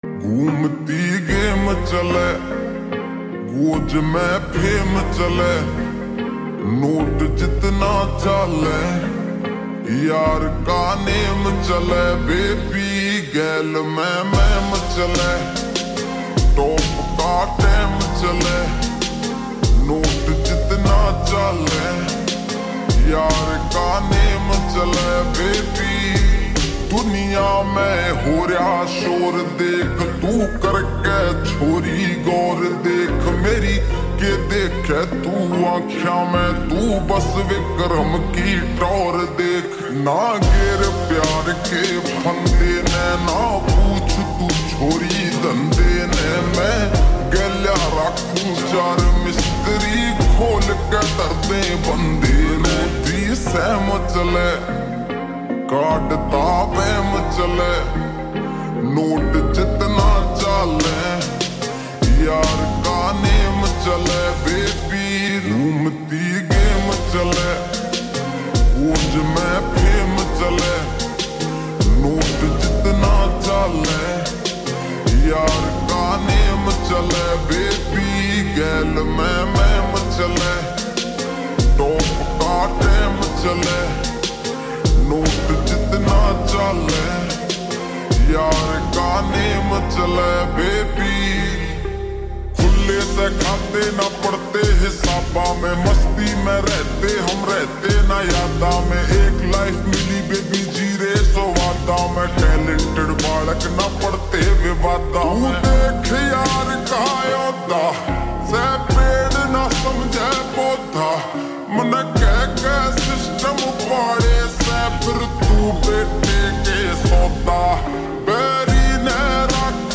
Trending Haryanvi Song Slowed and Reverb